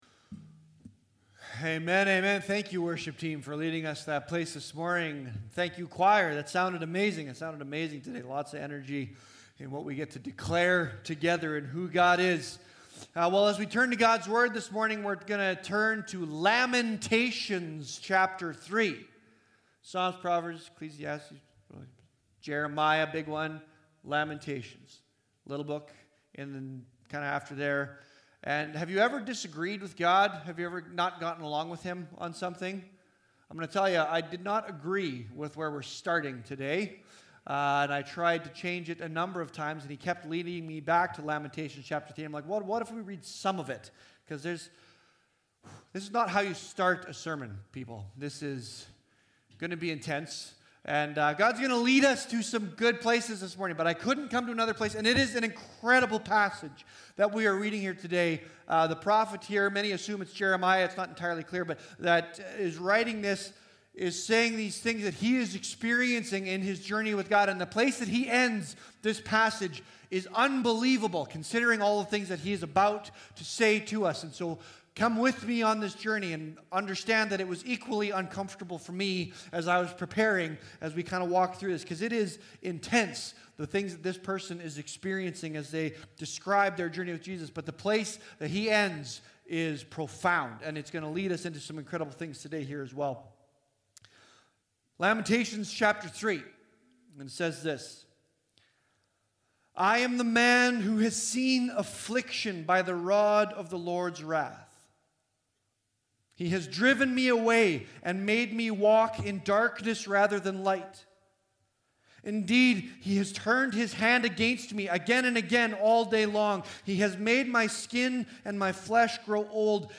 Sermons | Leduc Fellowship Church